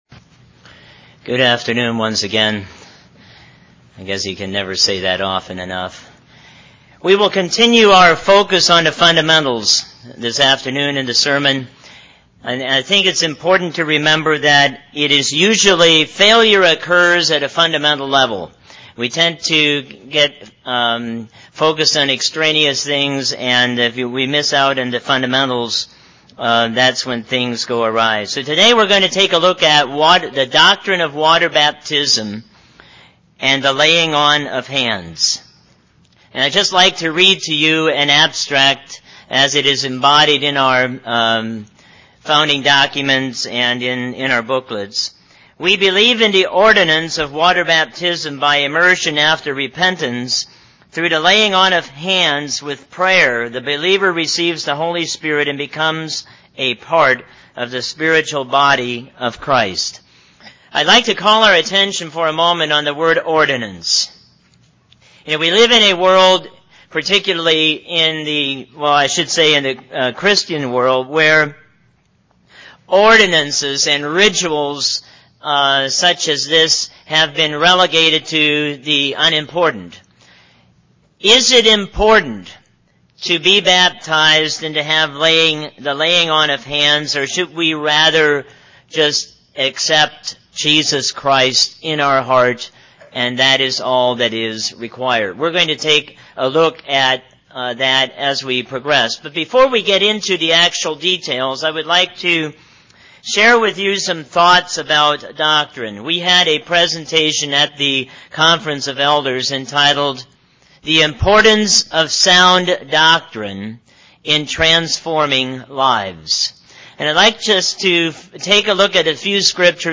Given in North Canton, OH
We look at 4 reasons why this ordinance of water baptism and the laying on of hands is important UCG Sermon Studying the bible?